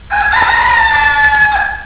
Rooster
ROOSTER.wav